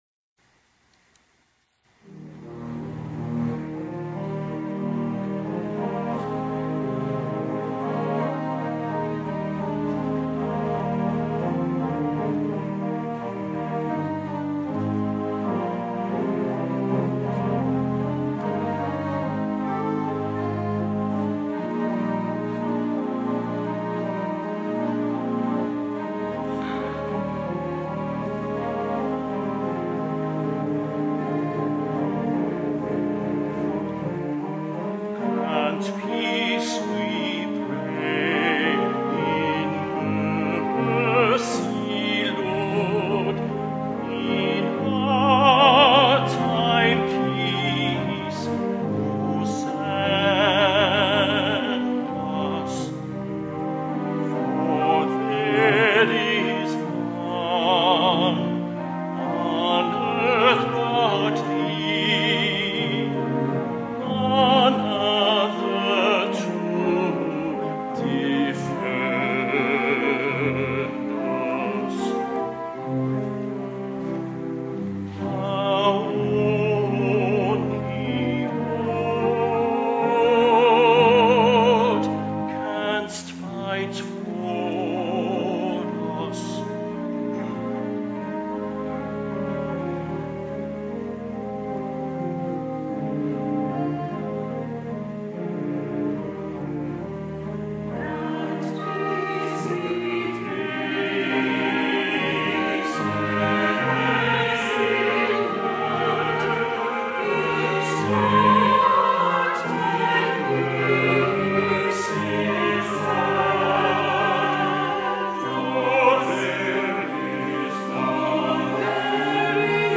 The Second Reformed Chancel Choir sings Felix Mendelssohn's "Grant Peace"